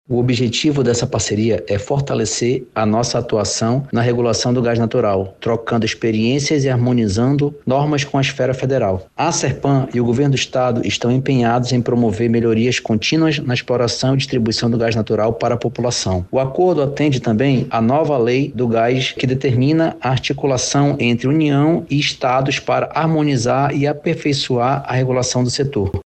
O diretor-presidente da Arsepam, Ricardo Lasmar, explica que a ação faz parte do Programa do Novo Mercado de Gás, instituído pelo Governo Federal.